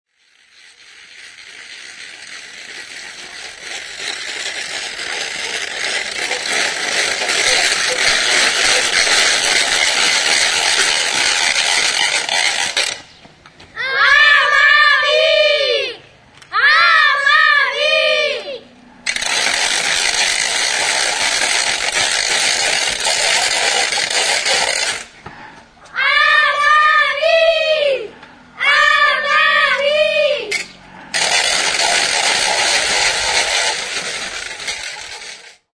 Instrumentos de músicaKARRAKA
Idiófonos -> Golpeados -> Sistema carraca
Idiófonos -> Frotados / friccionados